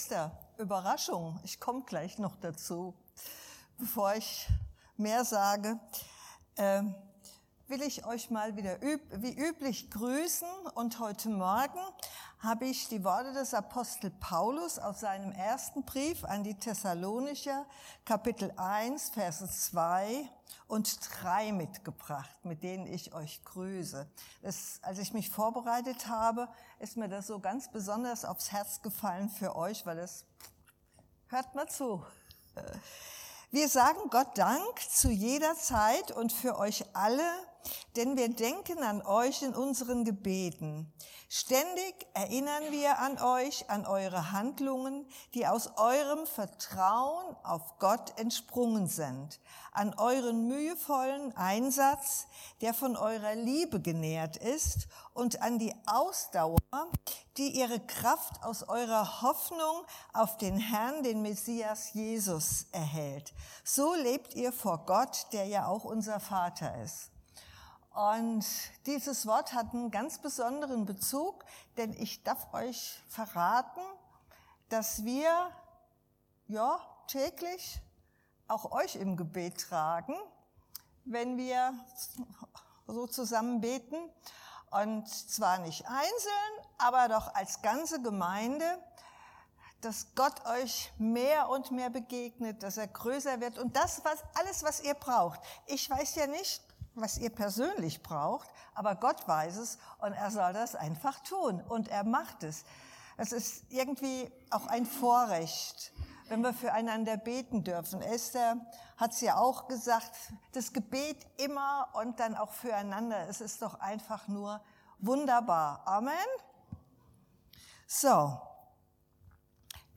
Dienstart: Predigt Was Gott zusagt das hält Er auch.